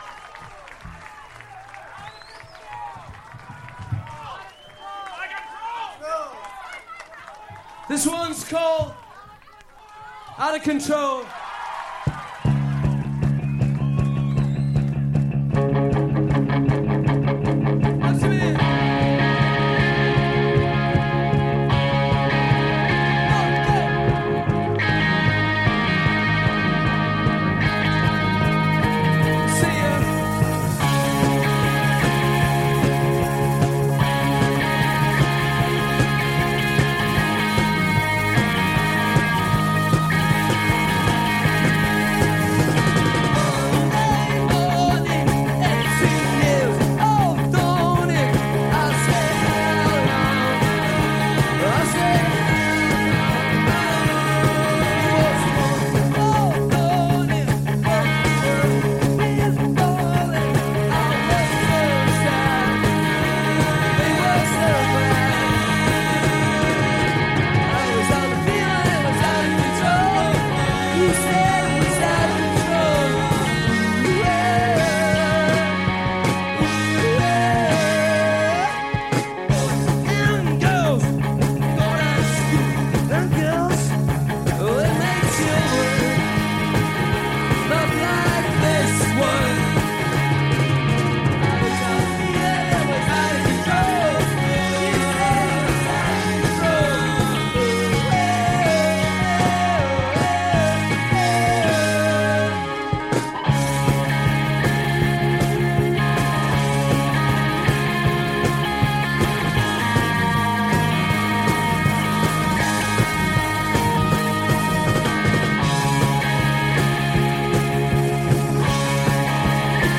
Live Boston